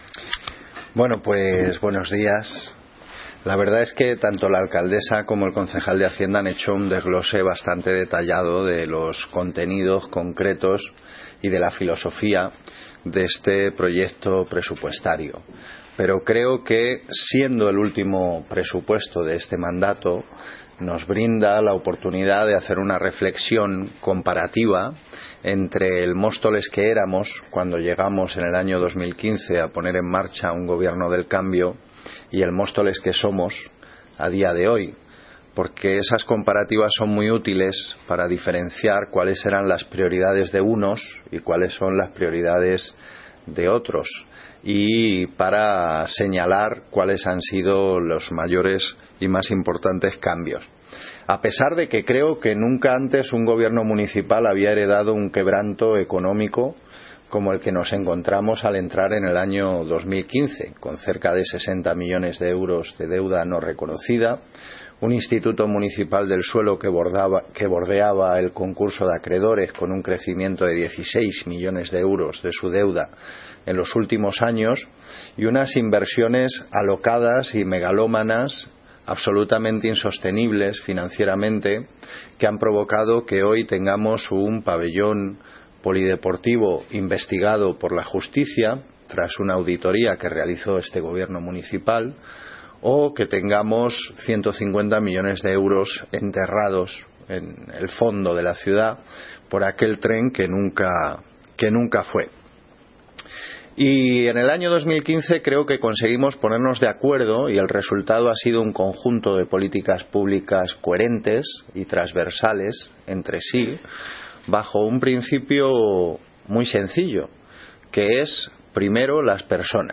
Audio - Gabriel Ortega (Concejal de Cultura, Bienestar Social y Vivienda) Sobre Presupuestos 2019